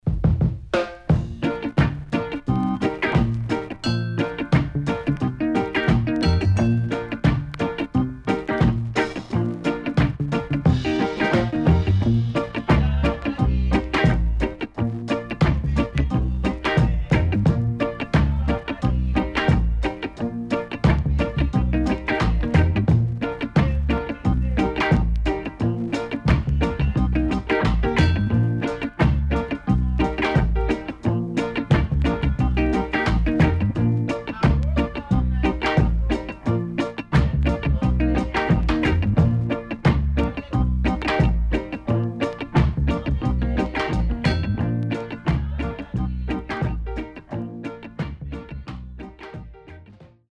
HOME > Back Order [VINTAGE 7inch]  >  EARLY REGGAE
Good Early Reggae
SIDE A:序盤ノイズありますが以降落ち着いてきます。